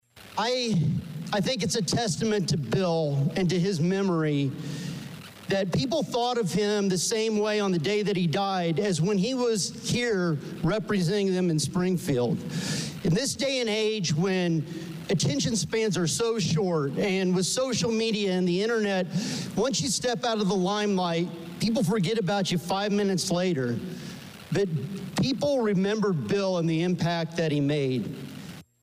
State Representative Mike Marron (R-Fithian) sponsored the resolution and spoke about his mentor on the House floor before its adoption.
COMMENTS FROM STATE REP MARRON: